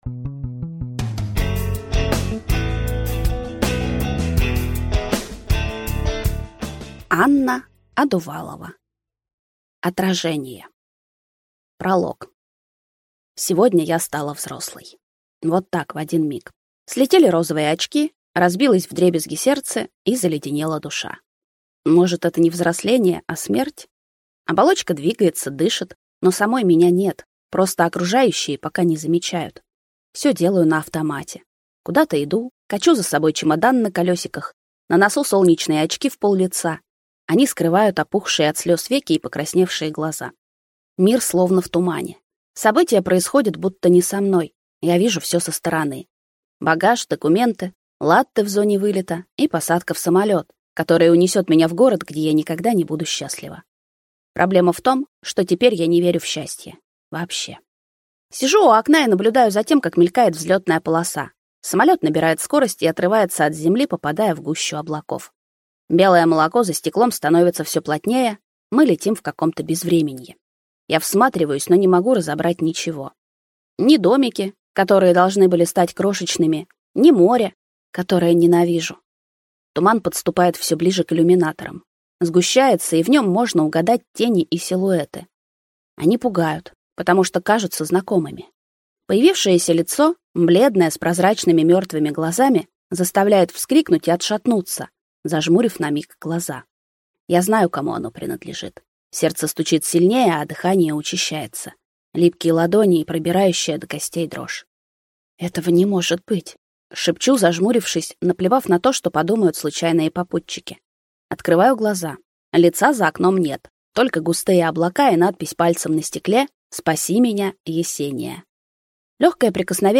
Аудиокнига Отражение | Библиотека аудиокниг